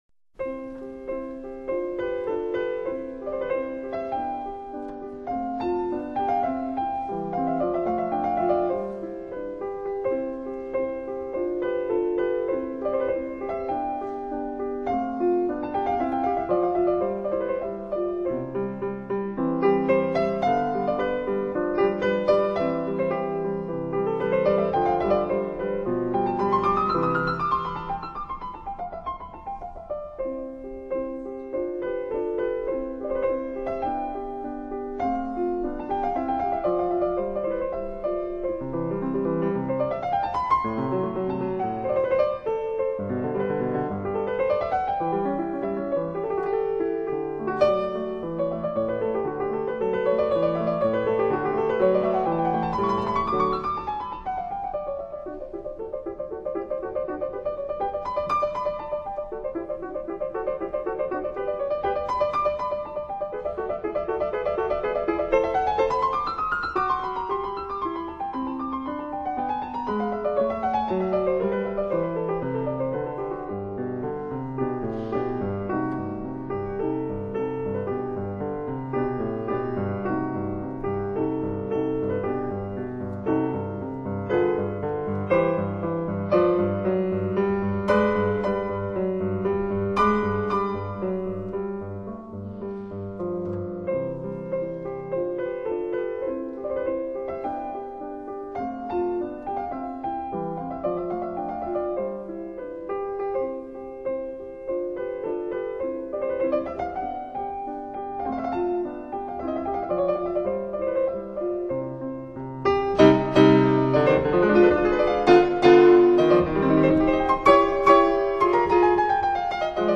前苏联钢琴家。